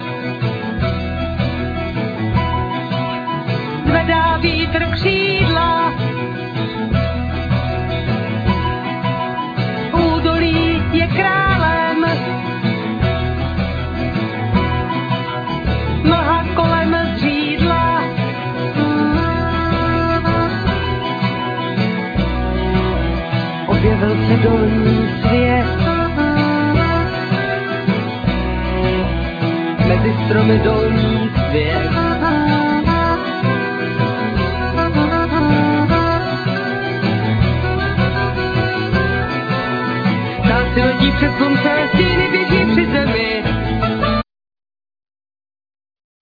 Vocal
Mandolin,Guitar
Saxophone,Clarinet,Keyboards,Whistle,Okarina
Cello,Violin,Bass